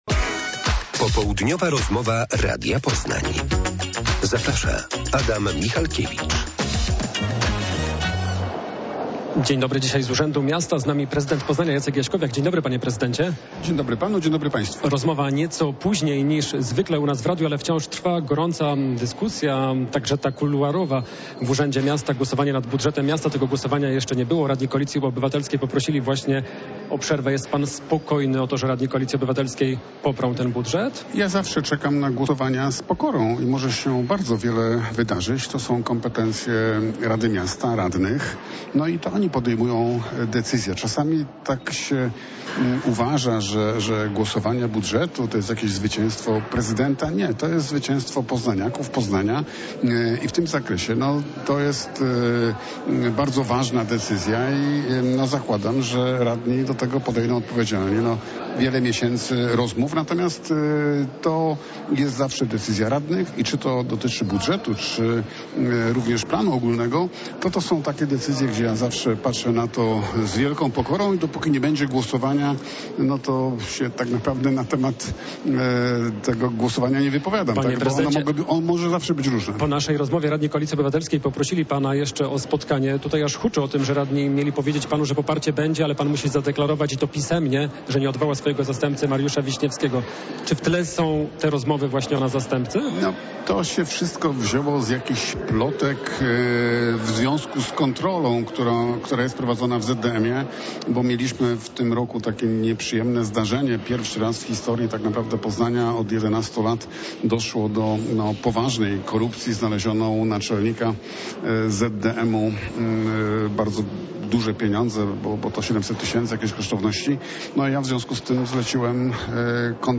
Popołudniowa rozmowa Radia Poznań – Jacek Jaśkowiak